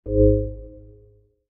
biometric_register_ready.ogg